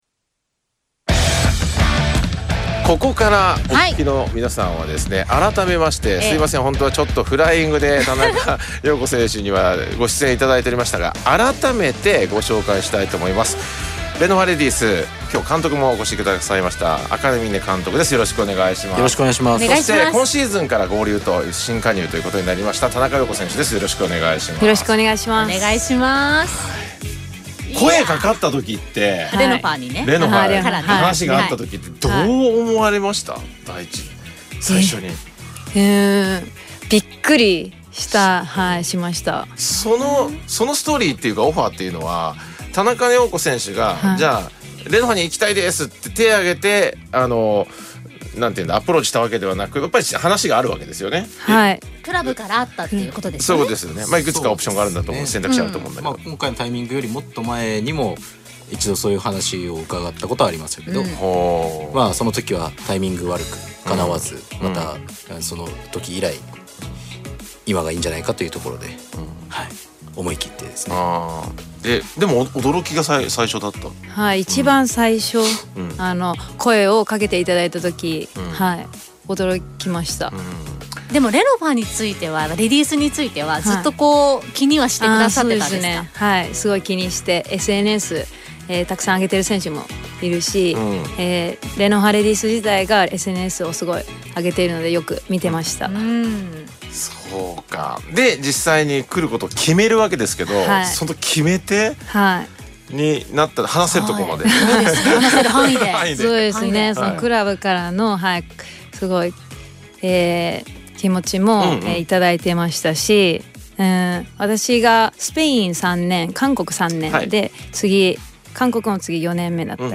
生出演